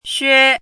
chinese-voice - 汉字语音库
xue1.mp3